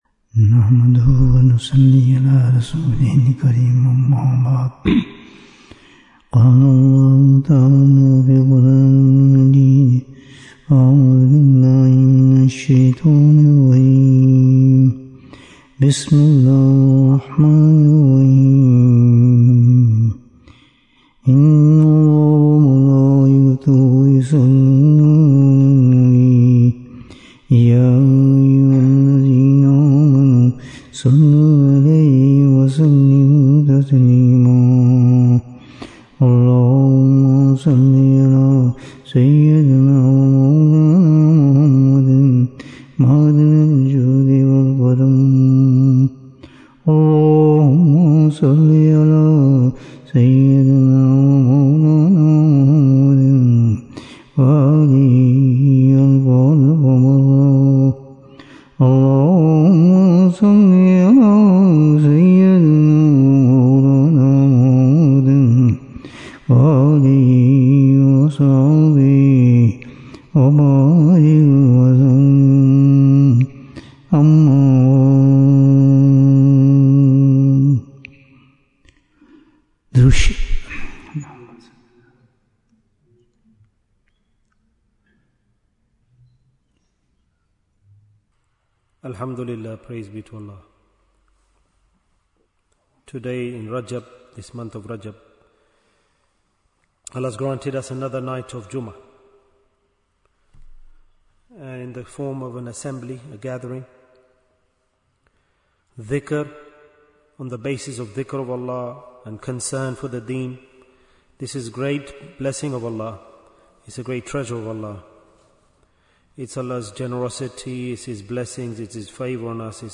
How do we Prepare for the Akhirah? Bayan, 77 minutes8th January, 2026